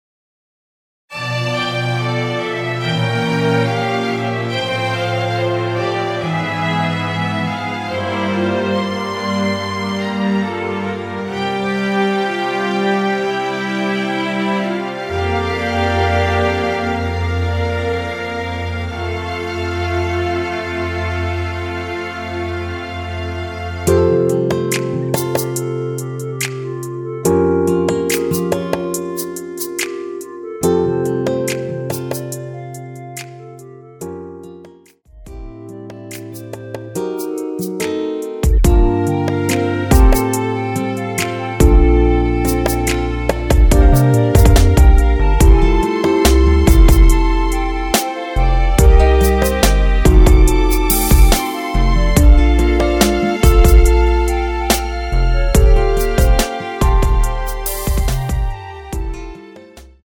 원키에서(+1)올린 멜로디 포함된 MR입니다.
주 멜로디만 제작되어 있으며 화음 라인 멜로디는 포함되어 있지 않습니다.(미리듣기 참조)
앞부분30초, 뒷부분30초씩 편집해서 올려 드리고 있습니다.
(멜로디 MR)은 가이드 멜로디가 포함된 MR 입니다.